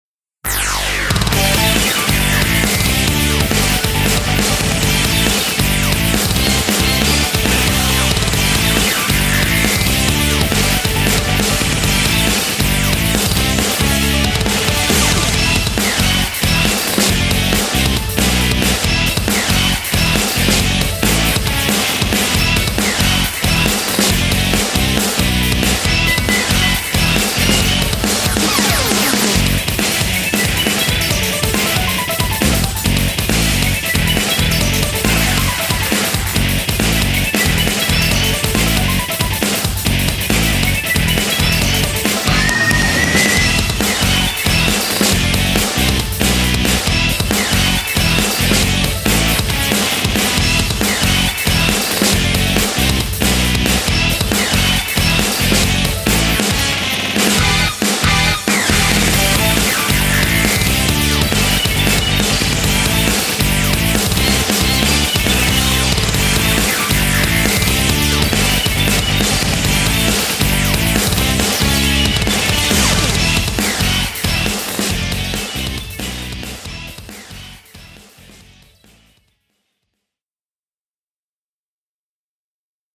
格闘ゲームのキャラクター選択画面のBGMを意識。